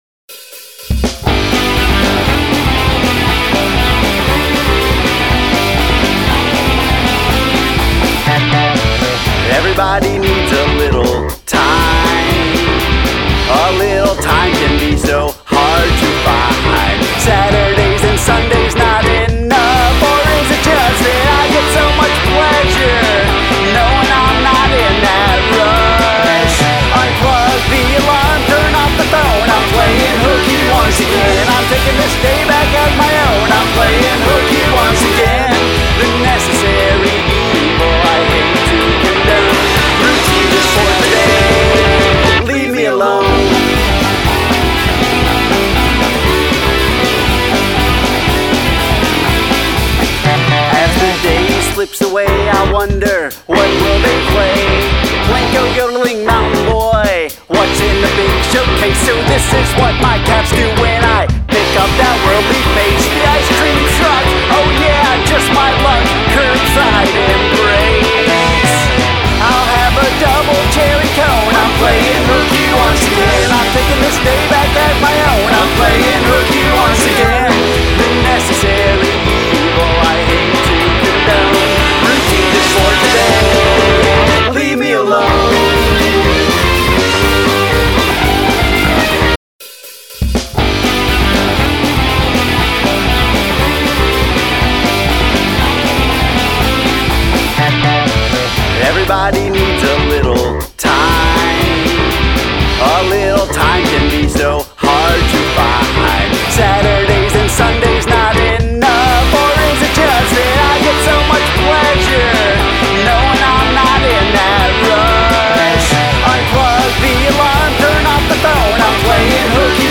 Quirkadelic Rock